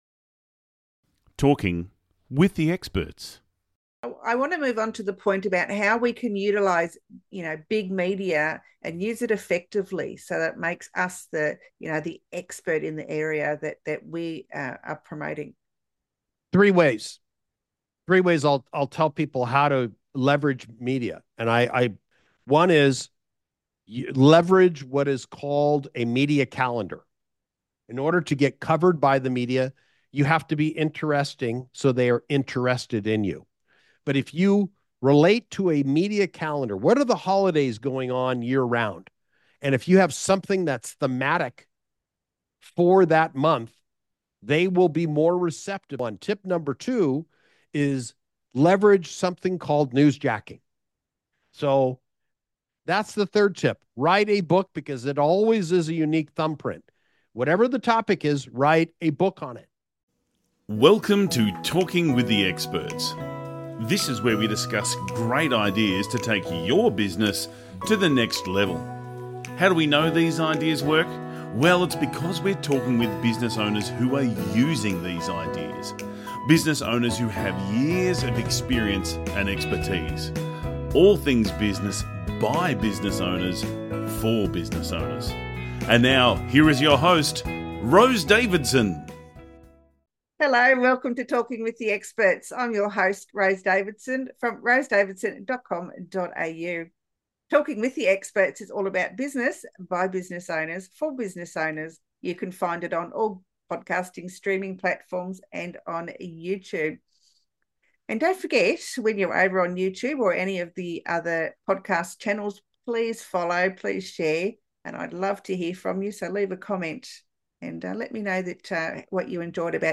Three Key Points from the Interview: How to Utilize Big Media for Business Growth – Learn the best strategies for landing TV and radio interviews to position yourself as an industry expert.